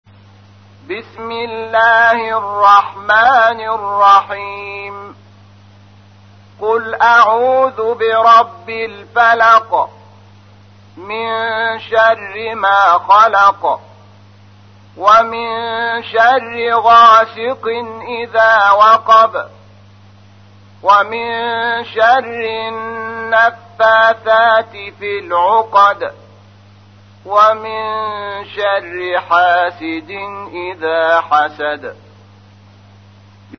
تحميل : 113. سورة الفلق / القارئ شحات محمد انور / القرآن الكريم / موقع يا حسين